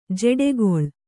♪ jeḍegoḷ